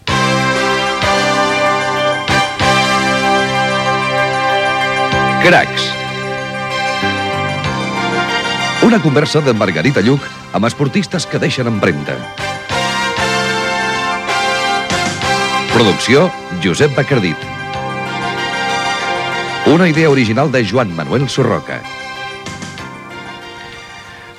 Careta del programa, amb els noms de l'equip.
Esportiu